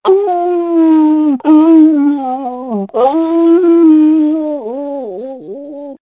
dghowl08.mp3